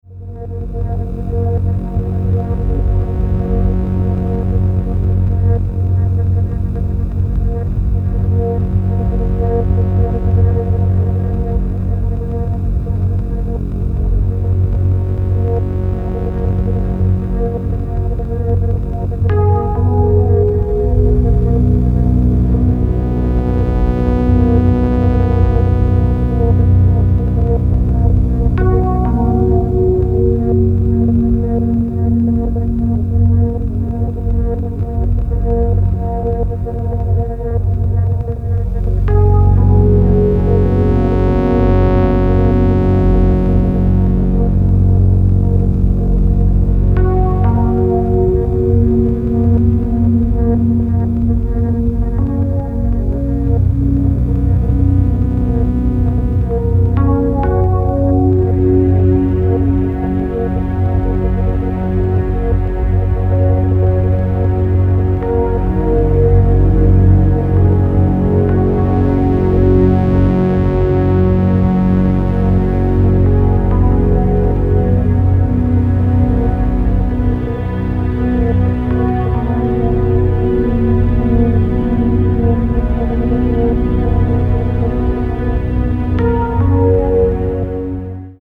AMBIENT/DOWNTEMPO